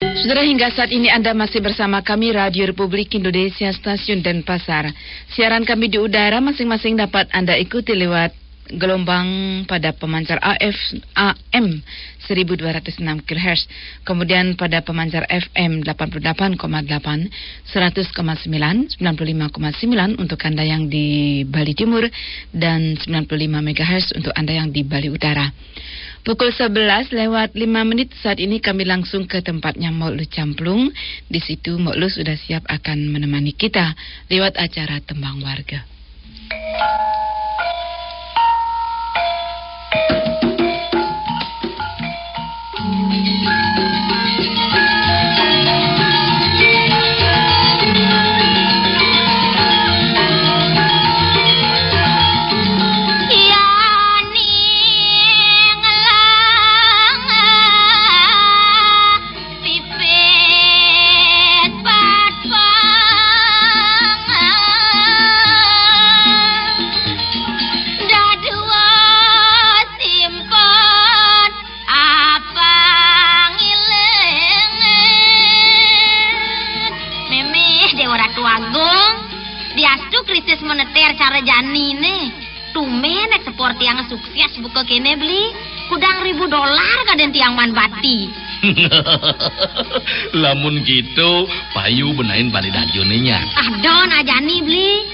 Frequency announcement and some germinal